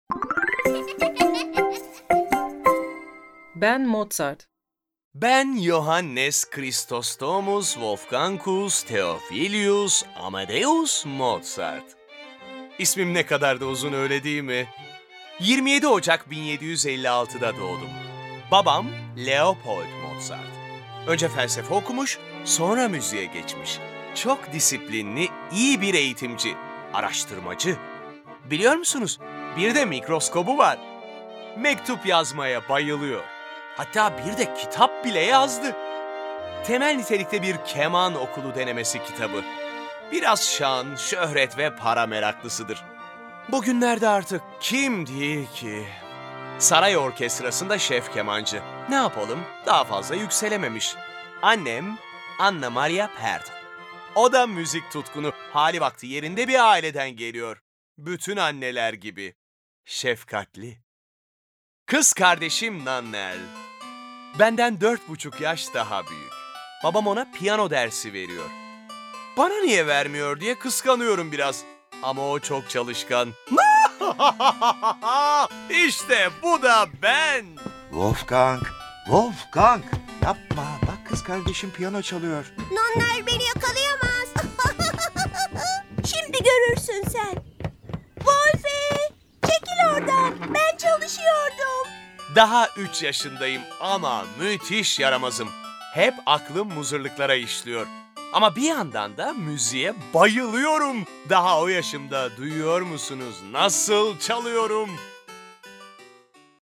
Bu hikayede, sadece ünlü müzik dehası Mozart'ın çocukluğundan gençliğine uzanan müzik serüvenini ve müziklerini dinlemeyecek; Mozart'ın ilgi çekici yaşam öyküsünde kendinizden çok şey bulacaksınız.